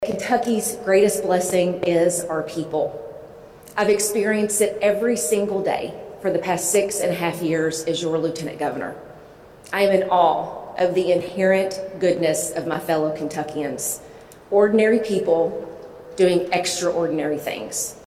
During a Monday morning press conference at the Kentucky History Center’s “Hall of Governors” in Frankfort, the 43-year-old from Mercer County announced her 2027 candidacy — a full year before the gubernatorial primary — while sending a clear message to the Commonwealth.